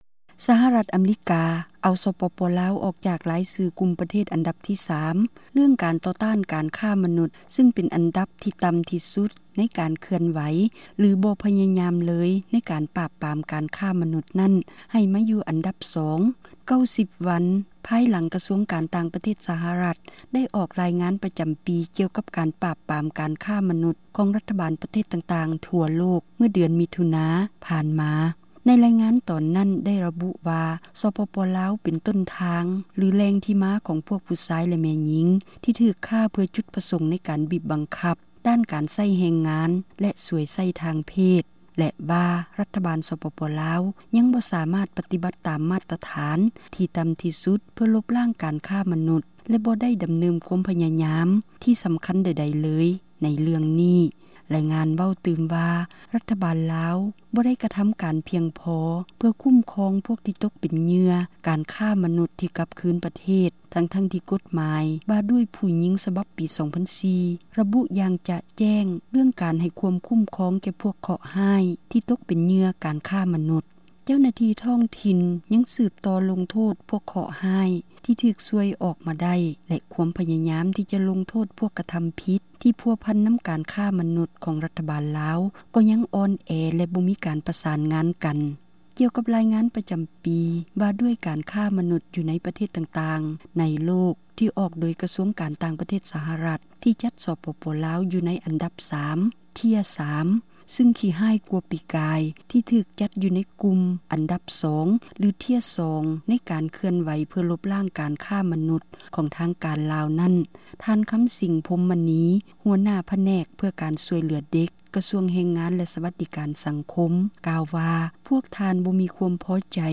ຂ່າວກ່ຽວກັບການຄ້າມະນຸດ
ຣາຍການຜູ້ຍິງແລະເດັກນ້ອຍສັປະດາຜ່ານມາ